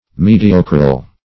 mediocral - definition of mediocral - synonyms, pronunciation, spelling from Free Dictionary Search Result for " mediocral" : The Collaborative International Dictionary of English v.0.48: Mediocral \Me"di*o`cral\, a. Mediocre.